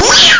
3:yeeow.mp3